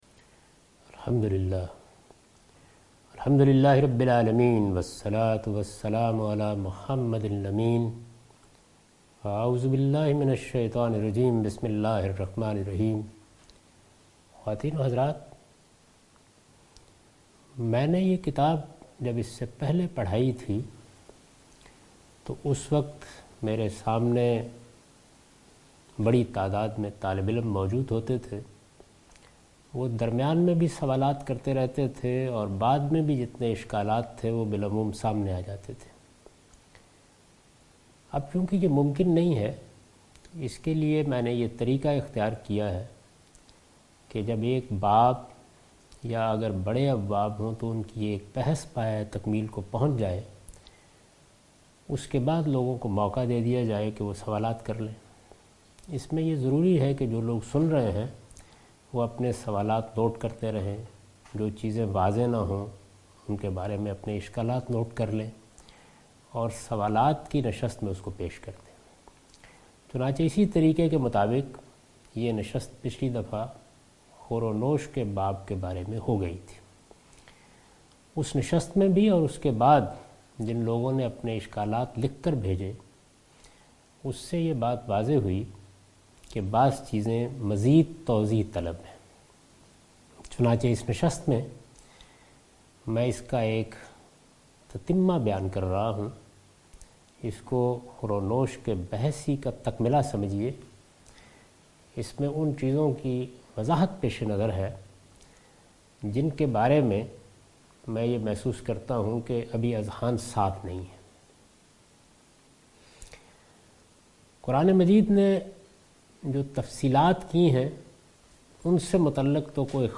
A comprehensive course taught by Javed Ahmed Ghamidi on his book Meezan. In this lecture he will discuss the dietary shari'ah. He explains Islamic law regarding edibles.